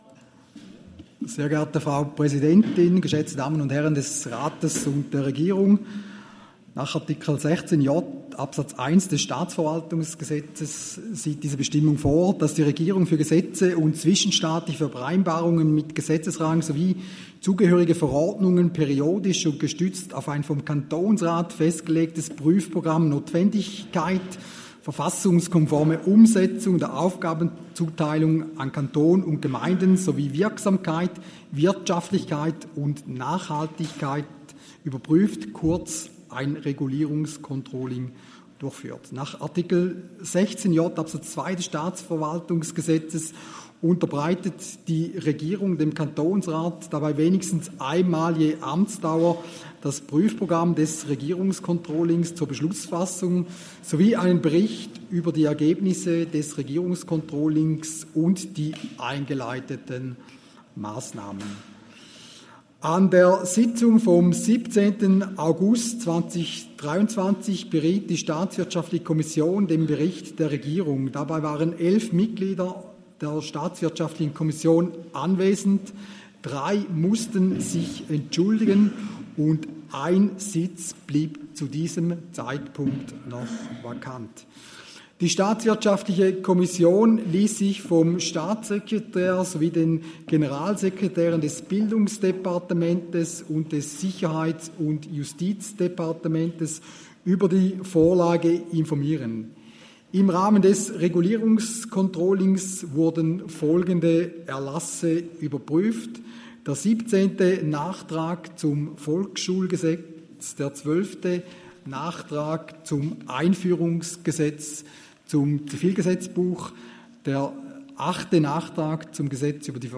Session des Kantonsrates vom 18. bis 20. September 2023, Herbstsession
19.9.2023Wortmeldung
Gemperli-Goldach, Präsident der Staatswirtschaftlichen Kommission: Die Staatswirtschaftliche Kommission beantragt, auf die Vorlage einzutreten.